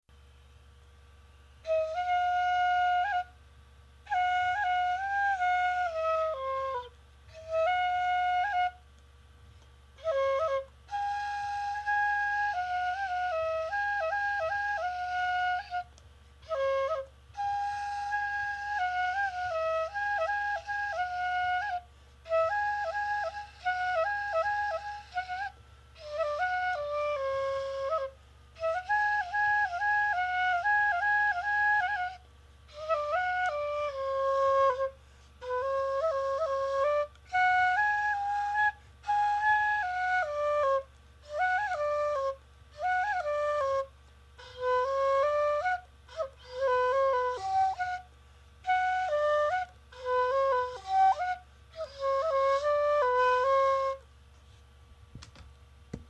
１、花輪ばやし　（笛→４調子７つ穴）
笛は未熟者で恐縮ですが、私の笛を録音させていただきました。
笛のメロディ